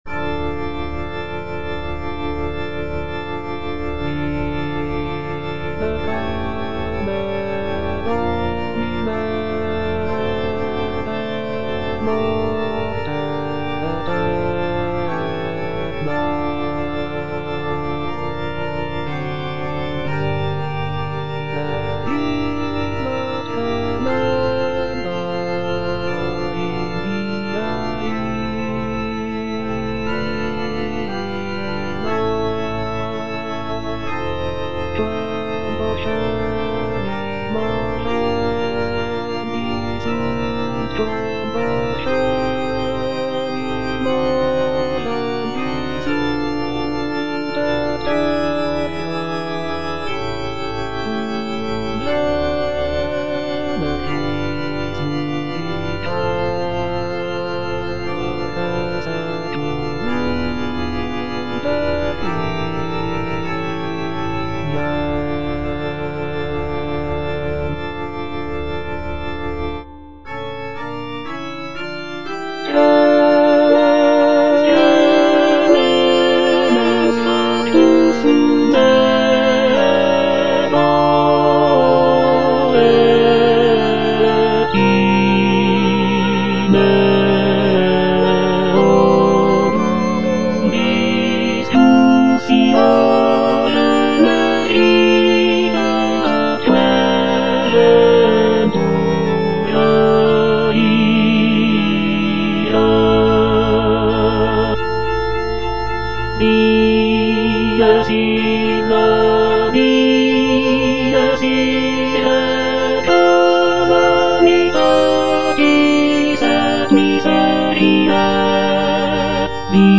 version with a smaller orchestra
tenor I) (Emphasised voice and other voices
choral-orchestral work